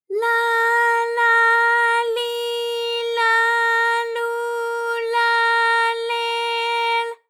ALYS-DB-001-JPN - First Japanese UTAU vocal library of ALYS.
la_la_li_la_lu_la_le_l.wav